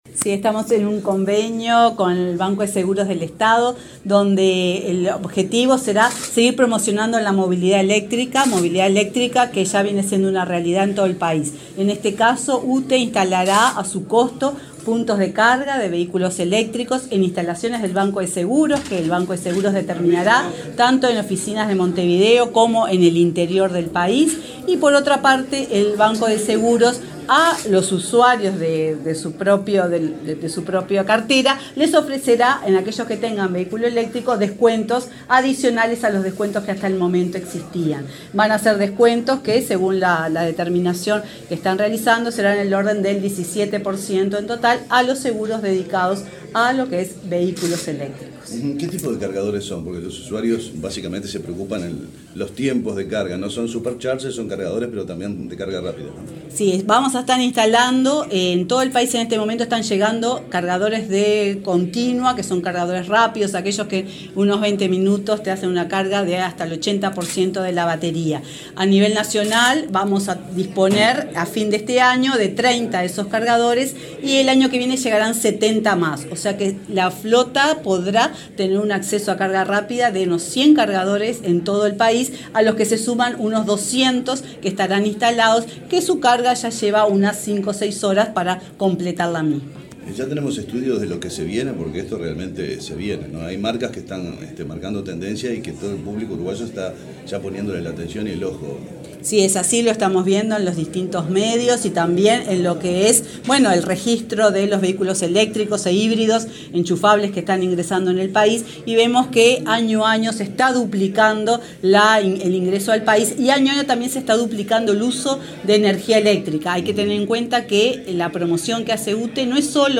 Declaraciones de la presidenta de UTE, Silvia Emaldi
Luego la titular del ente energético, Silvia Emaldi, dialogó con la prensa.